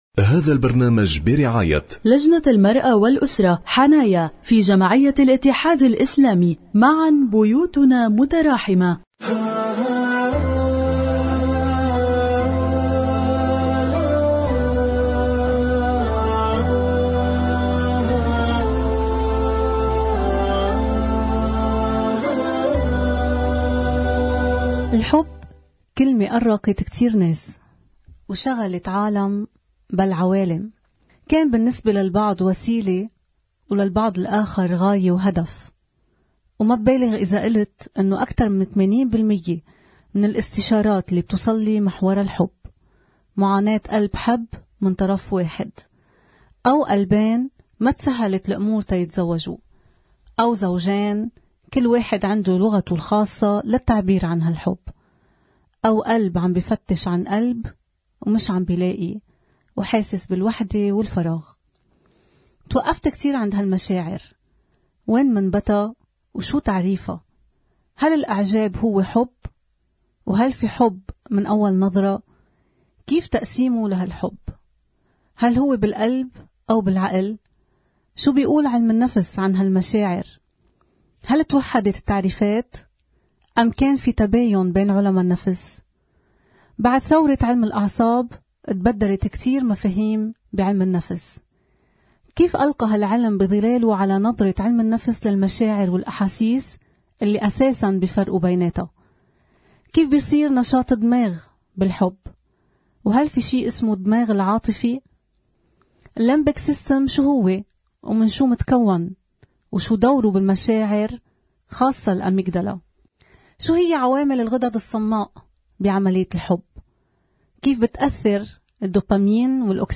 برعاية لجنة المرأة والأسرة-حنايا في جمعية الاتحاد الإسلامي على إذاعة الفجر.